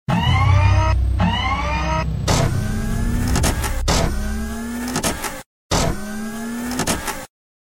Robot Movement Sound Effect
Category: Sound FX   Right: Personal